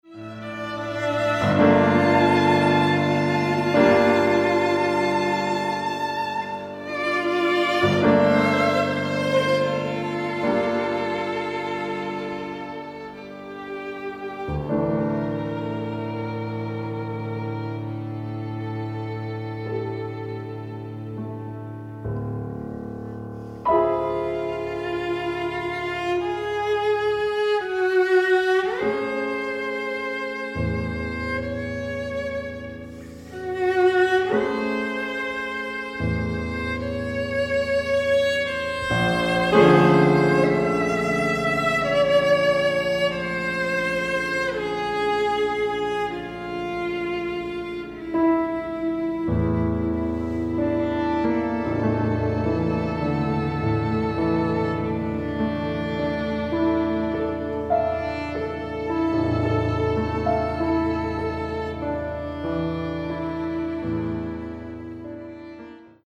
Australian classical music
for two guitars